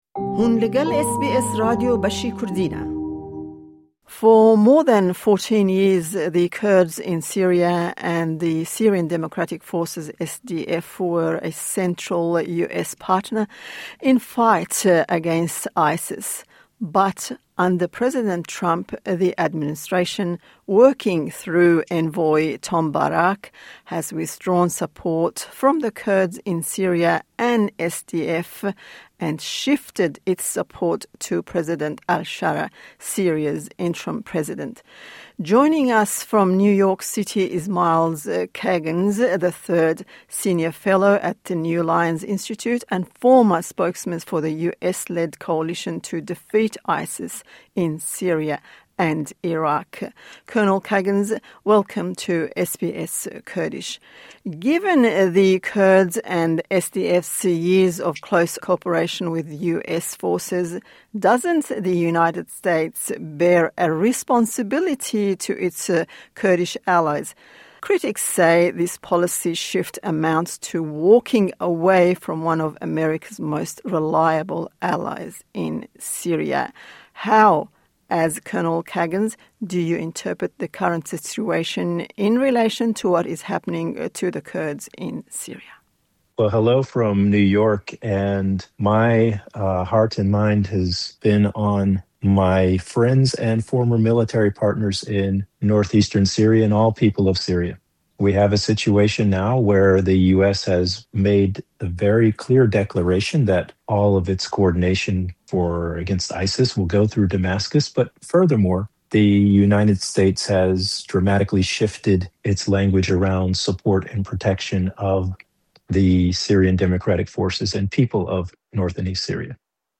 For over fourteen years, the Kurds and the Syrian Democratic Forces were central to U.S. efforts against ISIS. Recent shifts under the Trump administration, led by envoy Tom Barrack, indicate the U.S. is pulling back support from the Kurds and instead backing Syria’s interim president, Ahmed al-Shara. In an interview